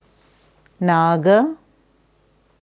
Naga नाग nāga Aussprache
Hier kannst du hören, wie das Sanskritwort Naga, नाग, nāga ausgesprochen wird: